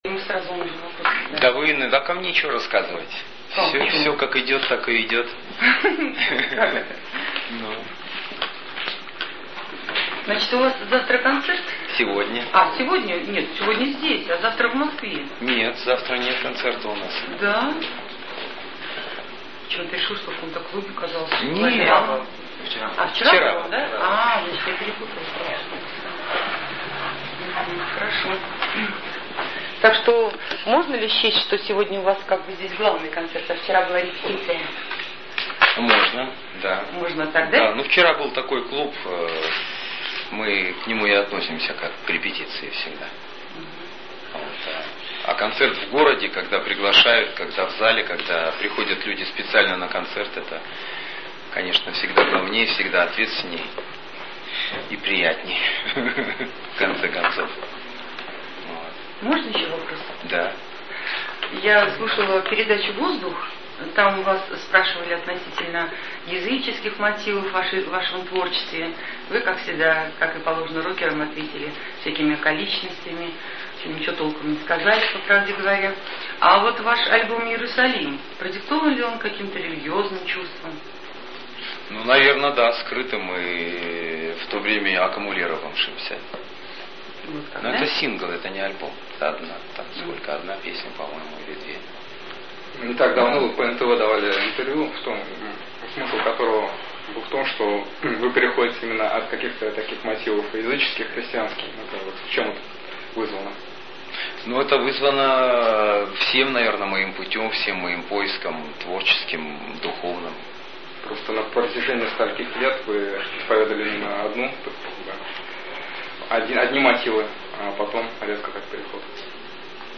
ИНТЕРВЬЮ С Дмирием Ревякином